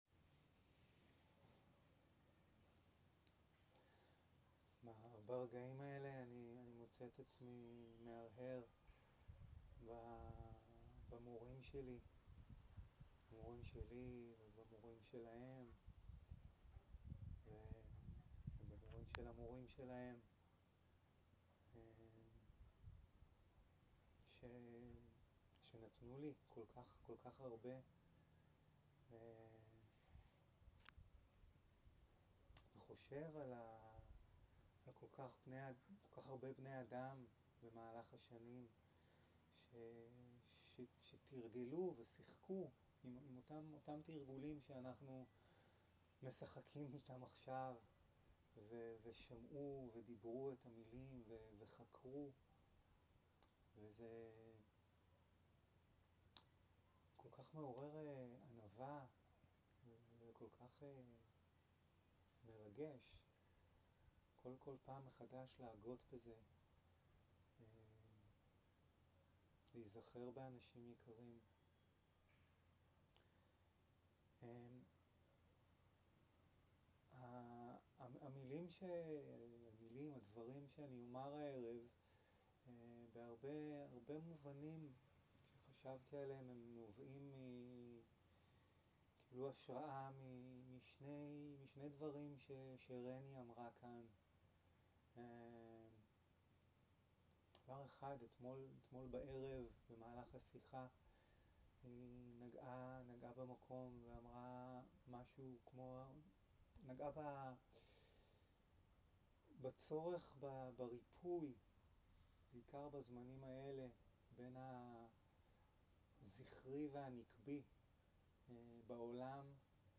שיחת דהארמה
Dharma type: Dharma Talks שפת ההקלטה